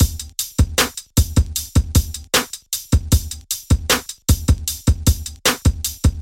丹尼布朗式鼓声循环
标签： 77 bpm Hip Hop Loops Drum Loops 1.05 MB wav Key : Unknown FL Studio
声道立体声